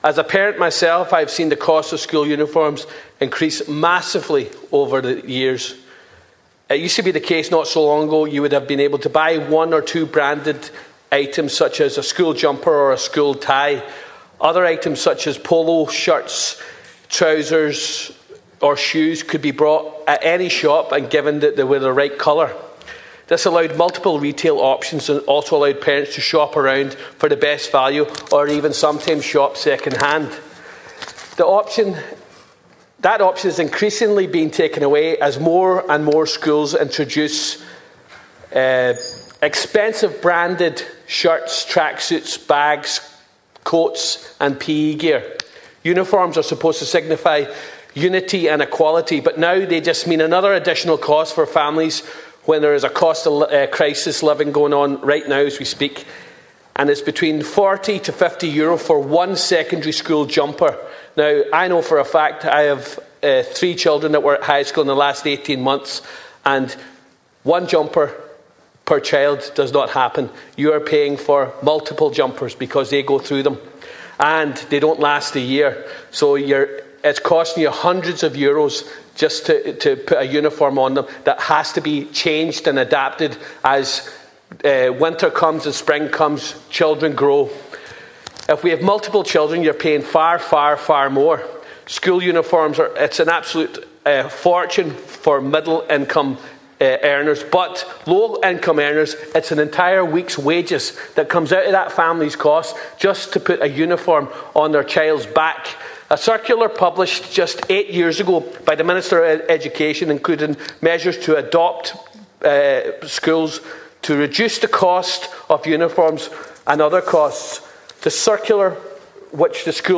You can hear Deputy Ward’s full speech here –